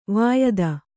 ♪ vāyada